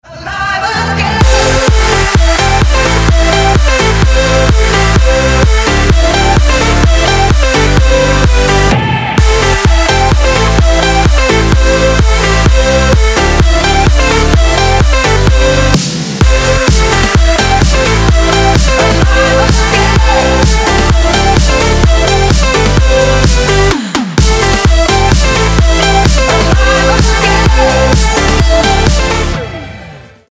• Качество: 256, Stereo
dance
club
progressive house